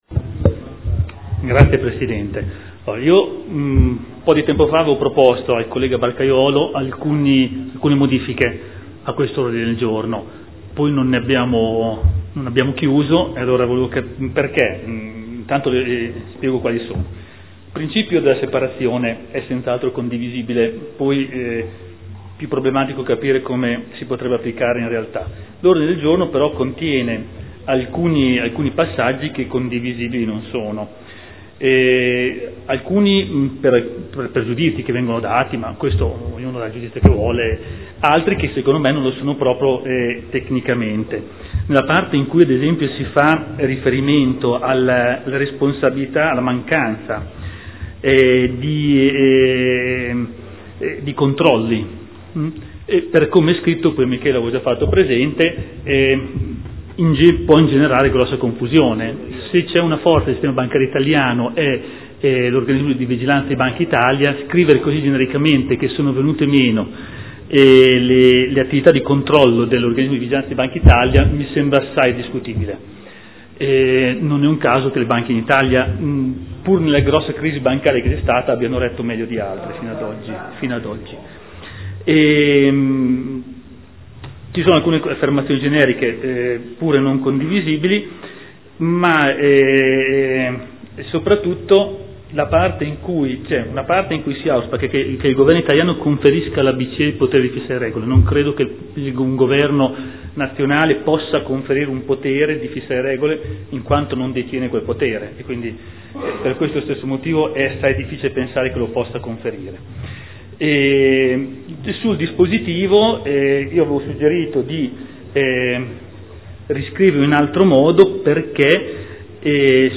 Gian Domenico Glorioso — Sito Audio Consiglio Comunale